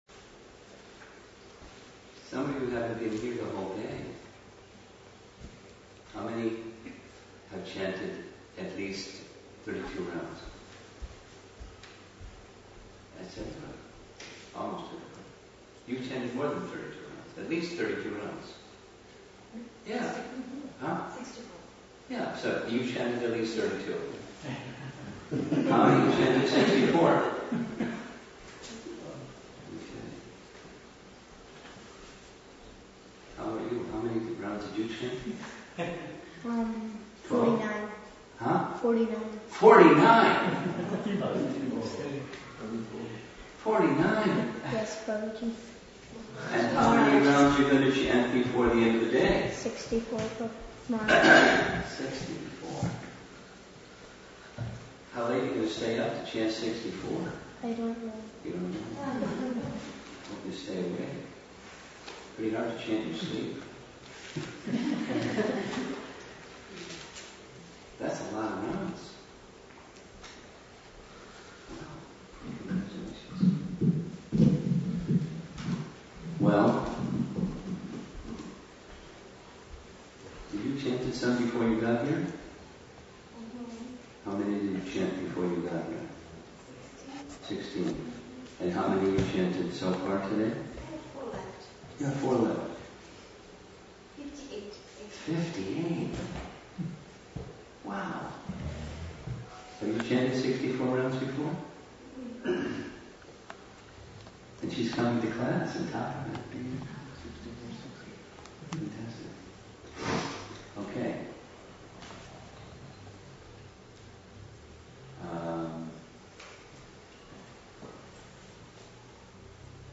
Lecture 2015 Seattle Japa Retreat